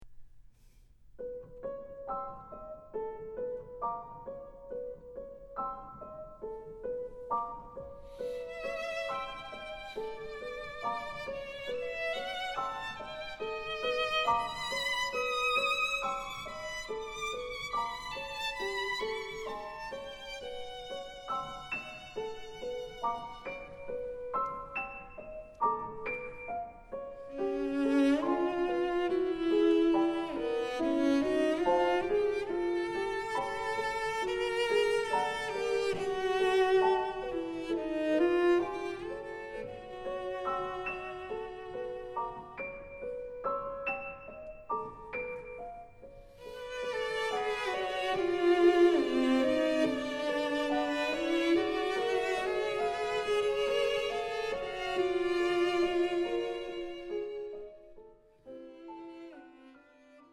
Quartet for Bassoon, Violin, Cello and Piano
Scherzo (2:55)